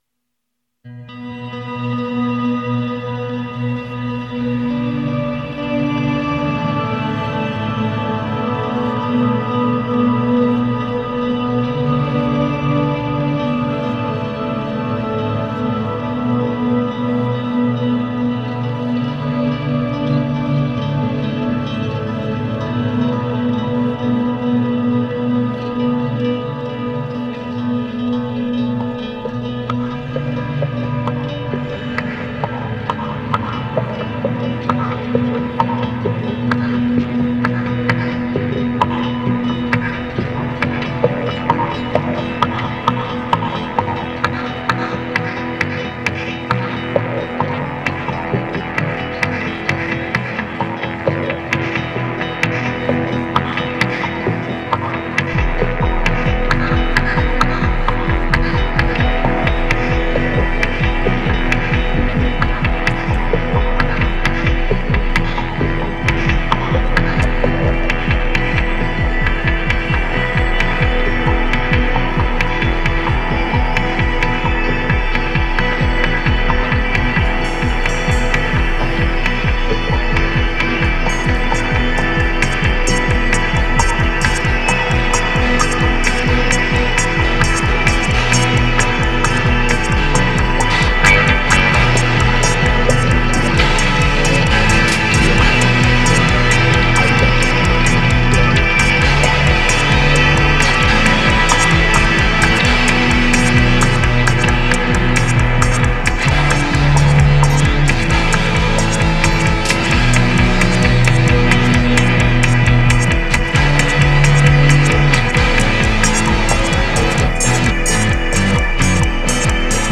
Wie auch immer, als ich den Eingangsthread las, schoss mir sofort ein Lied durch den Kopf das genau darauf passt. man kann Tanzen und schieben tut es auf jeden Fall.